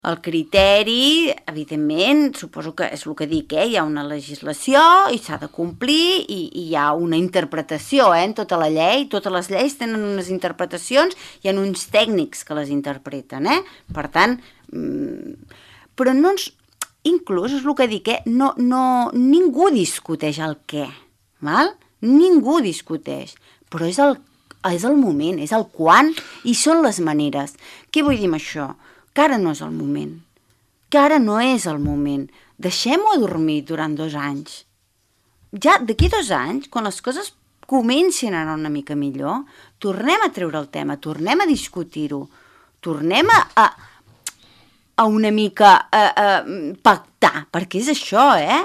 ha expressat en una entrevista a Ràdio Palafrugell el posicionament dels propietaris dels establiments del municipi.